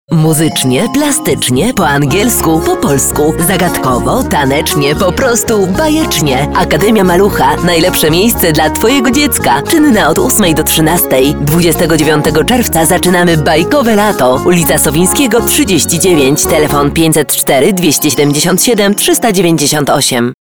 polnische Profi- Sprecherin für TV/Rundfunk/Industrie.
Sprechprobe: Industrie (Muttersprache):